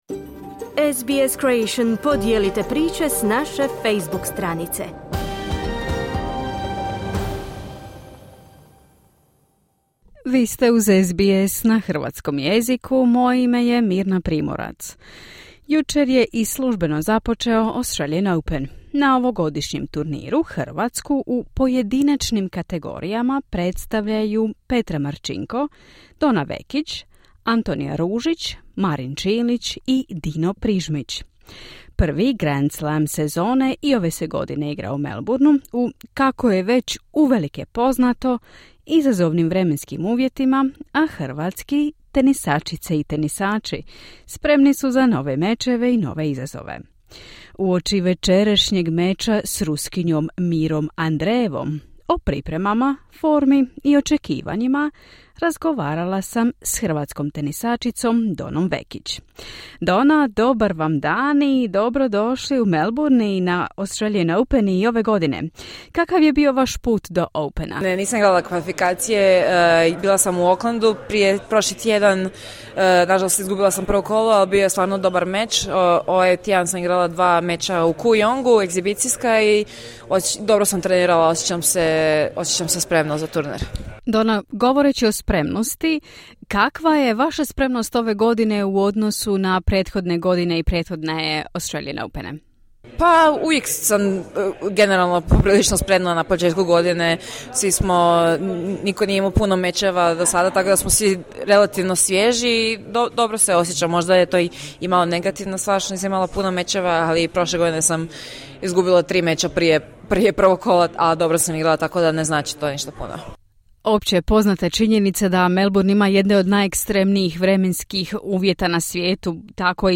Uoči večerašnjeg meča s ruskinjom Mirrom Andreeveom, Donna Vekić govori o pripremama, formi i očekivanjima.